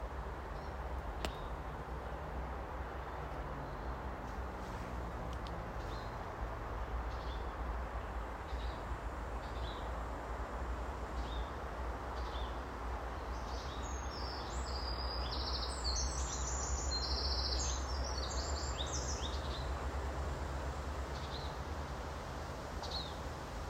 Putns (nenoteikts), Aves sp.
Administratīvā teritorijaĀdažu novads
StatussDzirdēta balss, saucieni